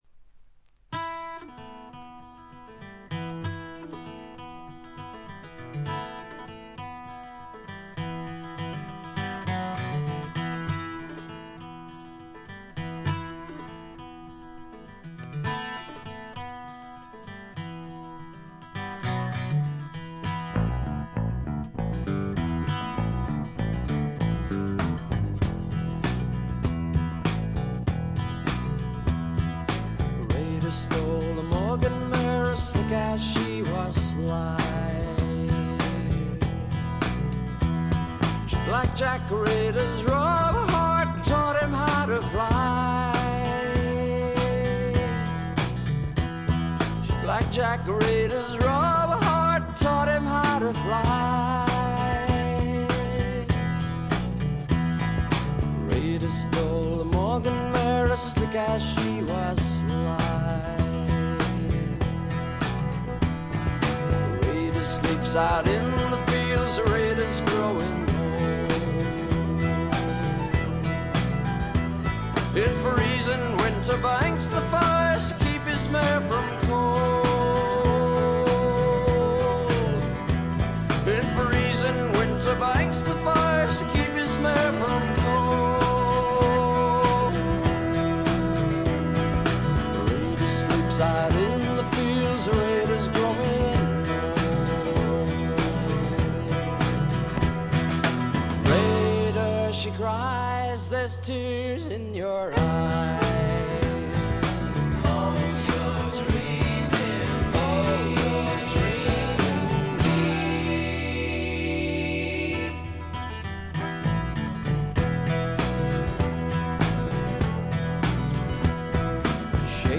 プカプカと牧歌的で枯れた味わいです。
mandolin, cello, mandola